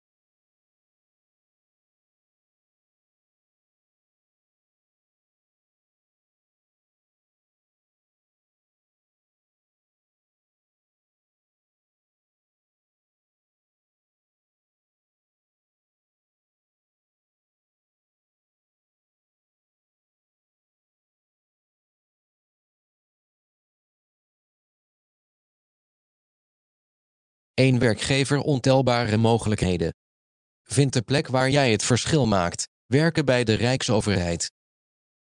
Deze promotievideo van de Rijksoverheid legt uit waarom werken voor de Rijksoverheid jou verder kan helpen en wat de mogelijkheden zijn.